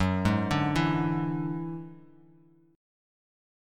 F#7sus2#5 chord